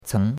ceng2.mp3